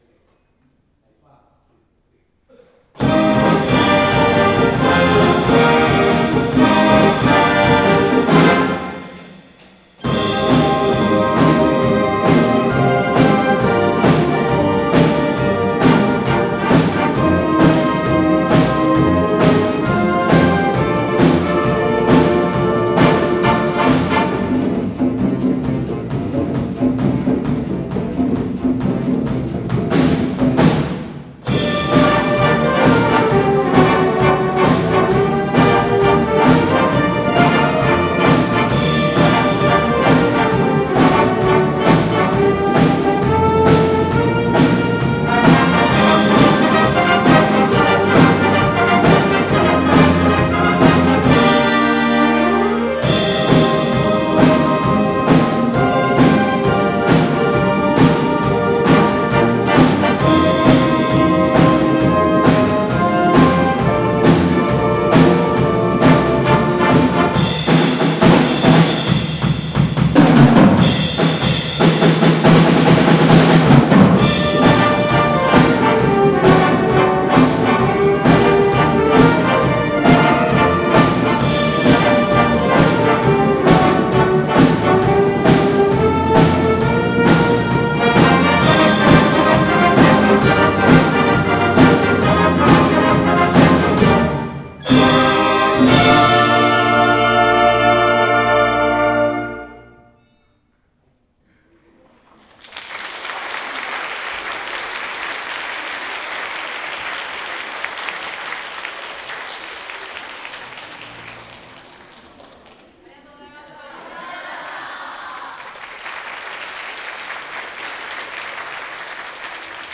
『第3回ホールは大騒ぎ(夜の部）』での
演奏を録音したものです。